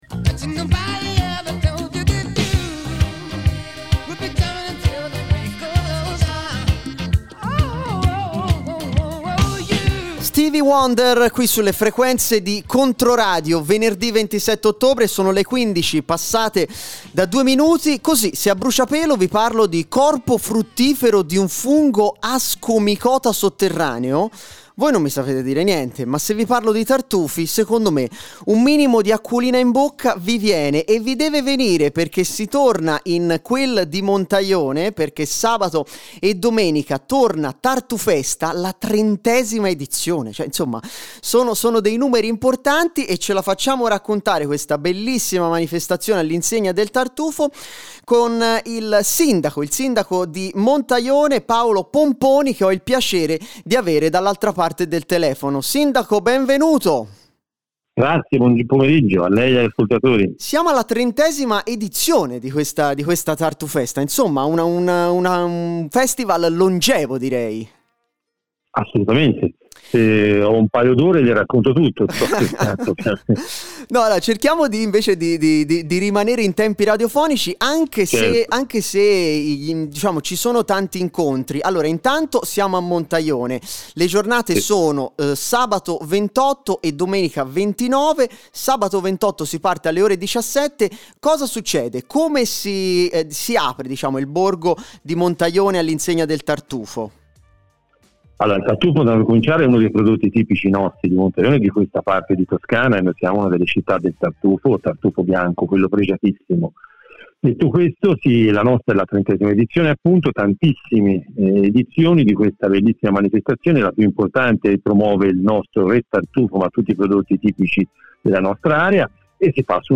🎧 Ascolta l’intervista al sindaco di Montaione Paolo Pomponi